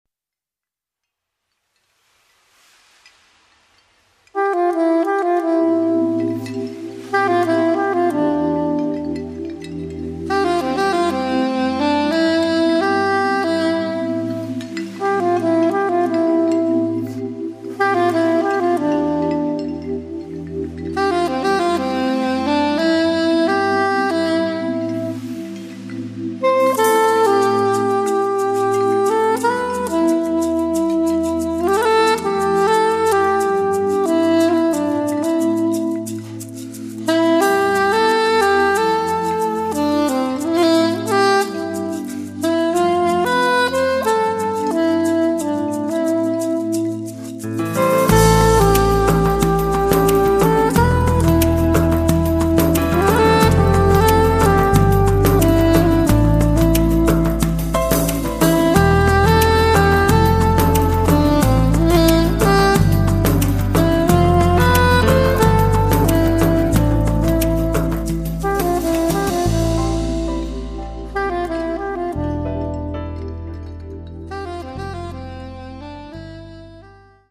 Sax, Tastiere, Synth, Percussioni
Piano
Basso